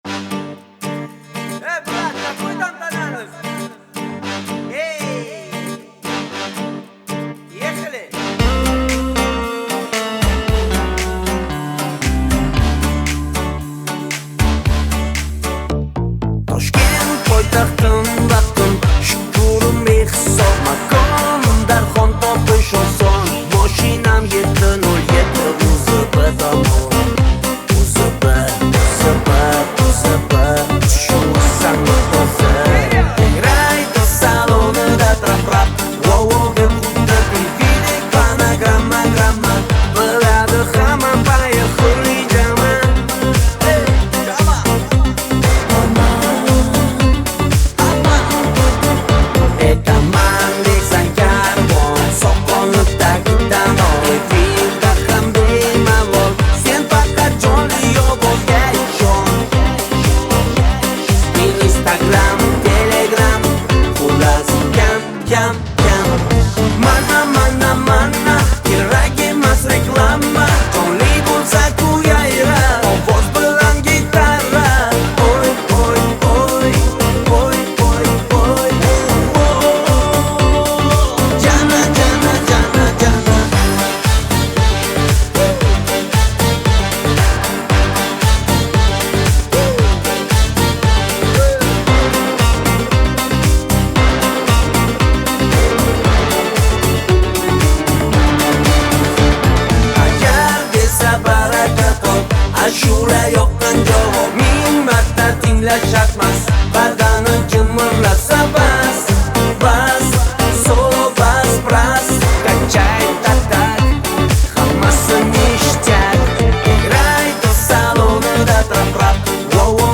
Жанр: Узбекские